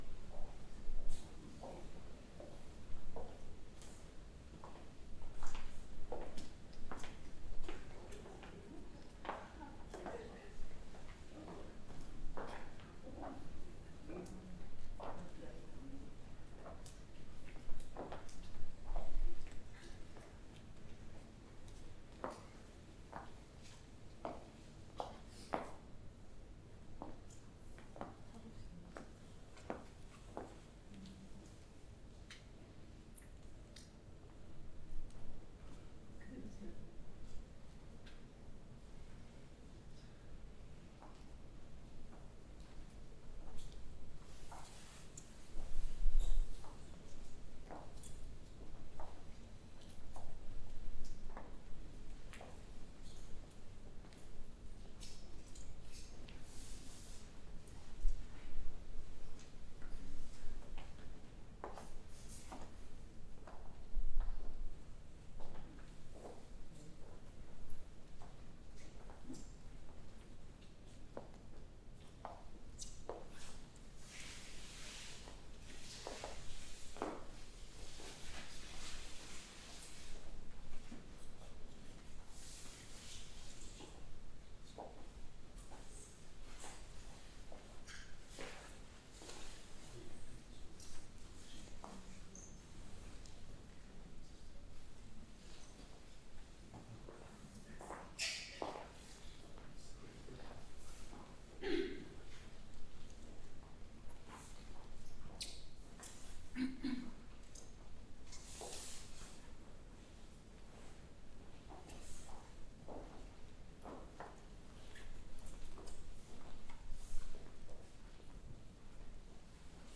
전시실04 발걸음.mp3